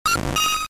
Cri de Mélodelfe K.O. dans Pokémon Diamant et Perle.
Catégorie:Cri de Mélodelfe Catégorie:Cri Pokémon K.O. (Diamant et Perle)